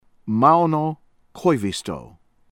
KIVINIEMI, MARI MAH-ree   kee-vee-NYEH-mee